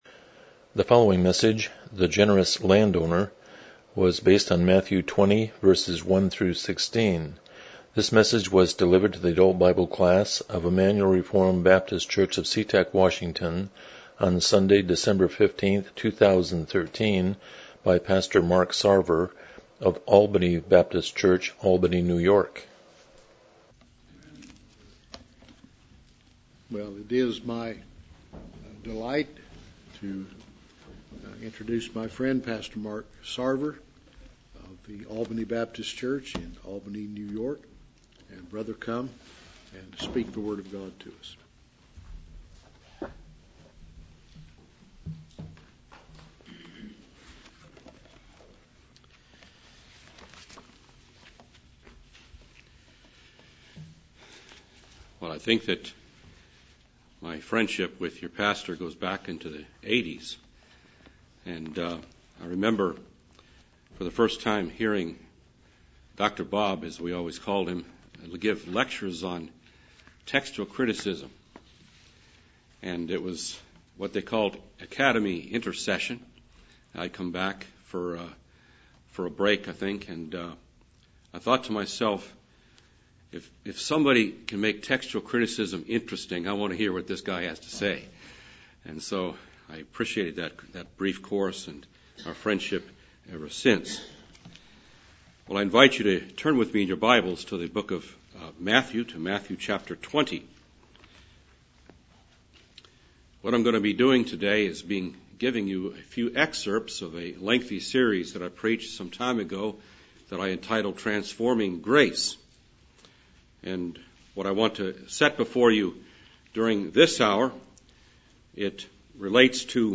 Miscellaneous Passage: Matthew 20:1-16 Service Type: Sunday School « 10 What Makes Man Unique?